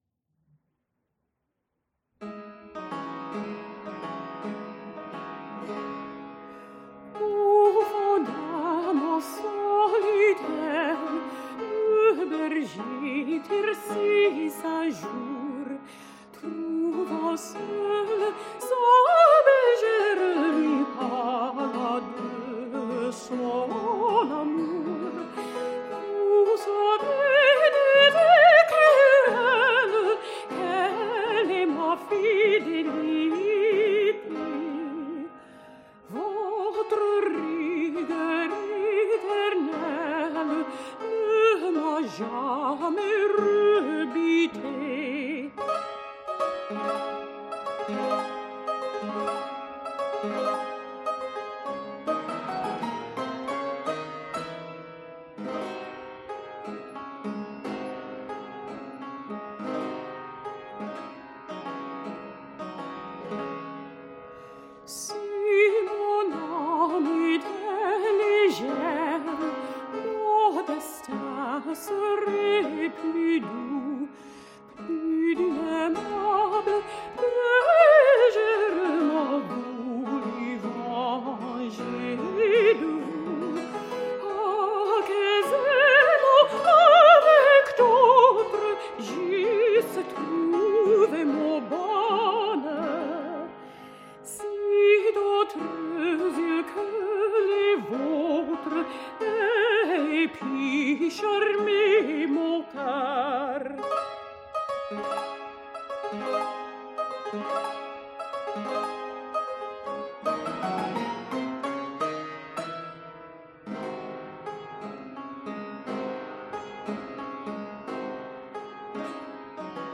PerformerThe Raritan Players
Subject (lcsh) Romances (Music)